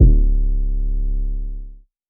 808 OG Parker.wav